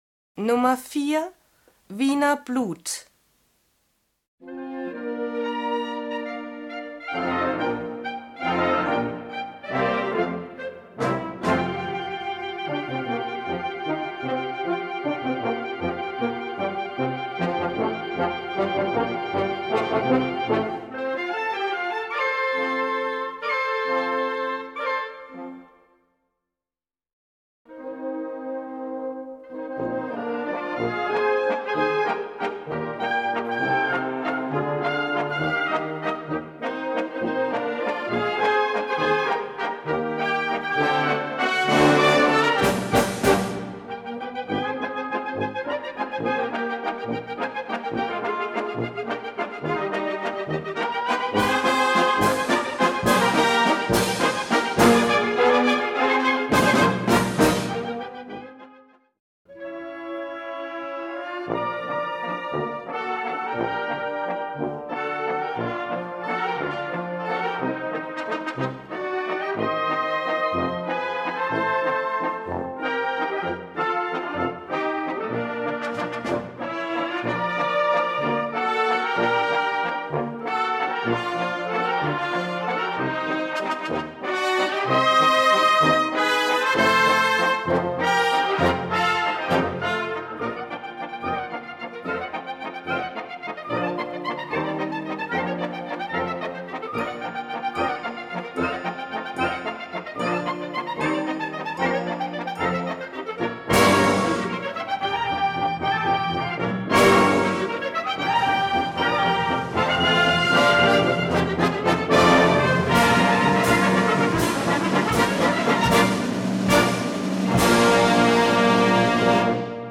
Gattung: Konzertwalzer
Besetzung: Blasorchester